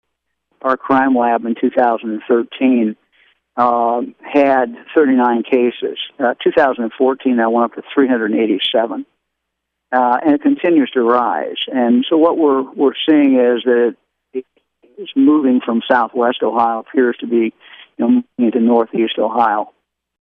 Attorney General Mike DeWine says crime labs and coroners are finding fentanyl in more heroin and in more overdose victims.